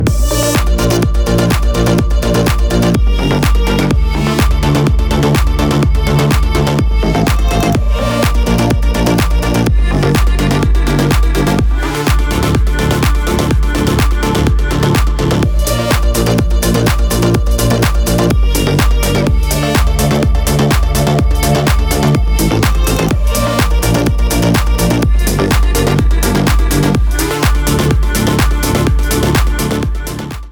• Качество: 320, Stereo
dance
EDM
Electronica
house